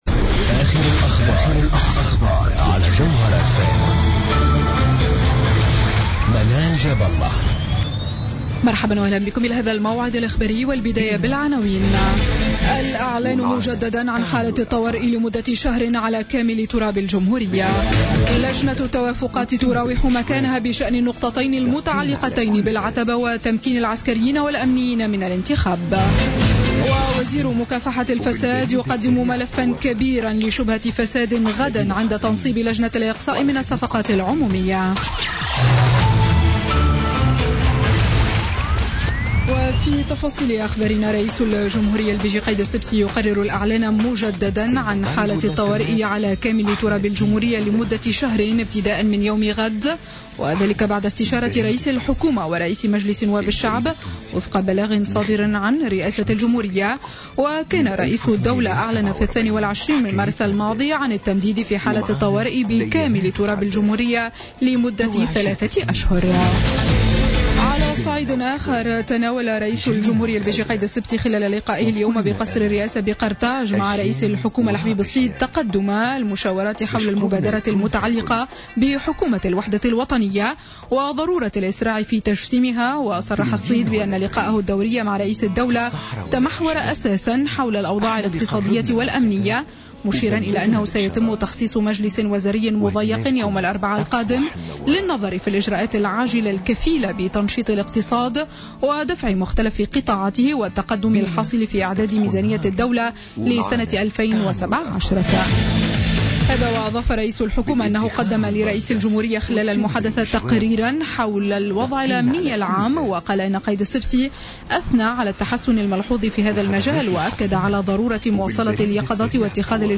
نشرة أخبار الخامسة مساء ليوم الإثنين 20 جوان 2016